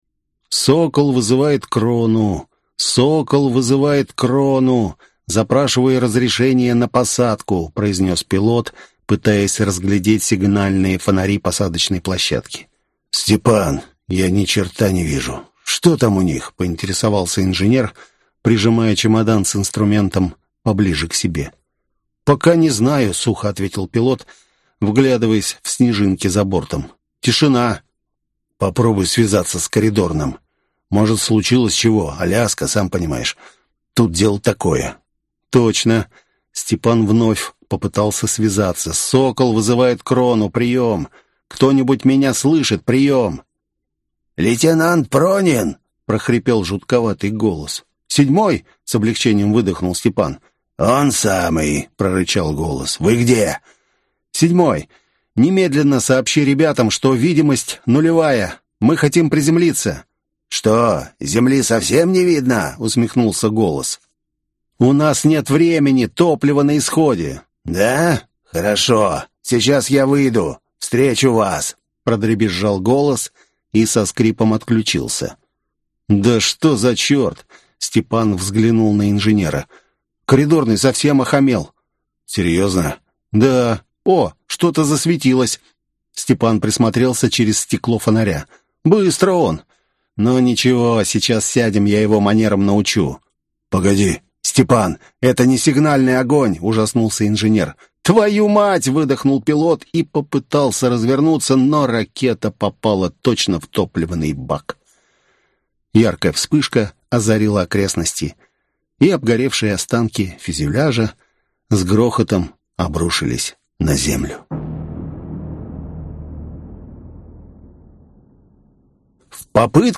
Аудиокнига Горизонт событий. Книга 2. Вспышка Сверхновой | Библиотека аудиокниг